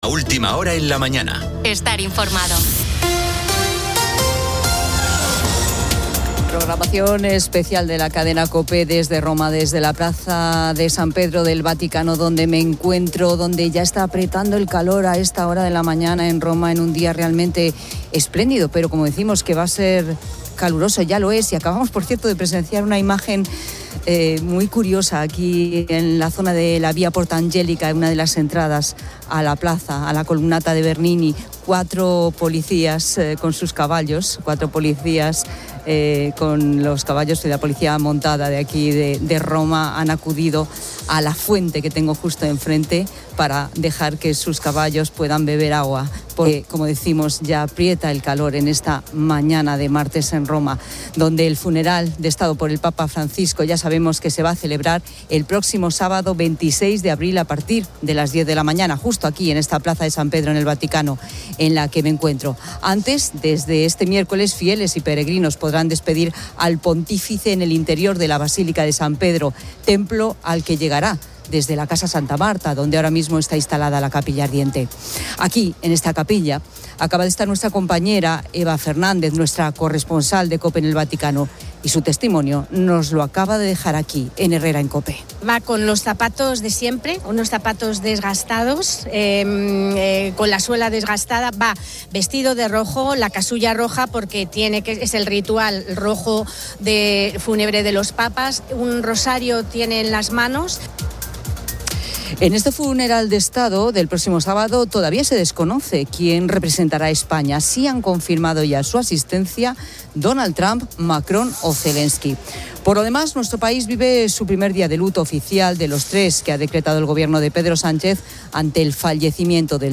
Programación especial de la cadena Cope desde Roma, desde la Plaza de San Pedro del Vaticano, donde...